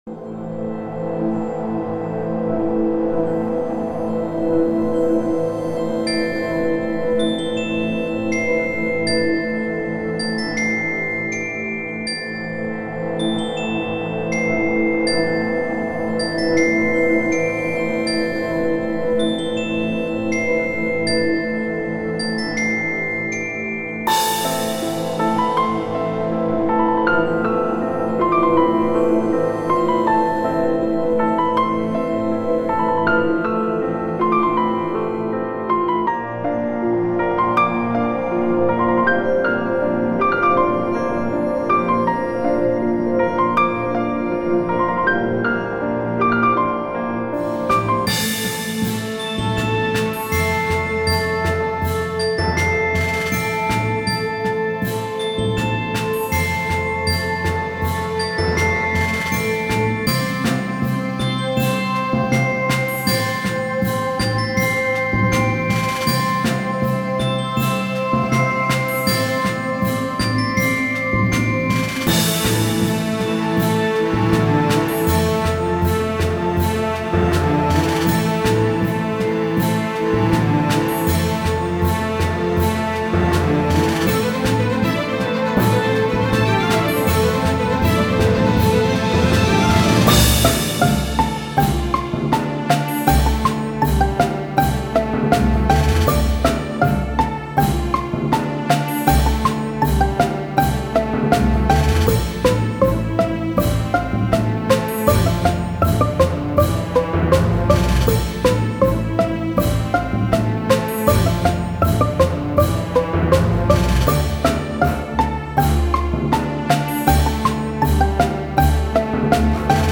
ΟΡΧΗΣΤΡΙΚΑ